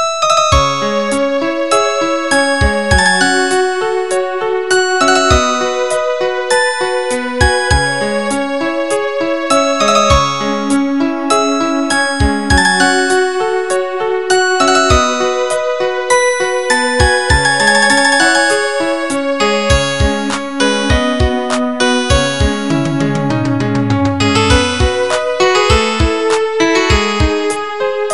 Kategorien: Klassische